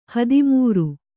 ♪ hadimūru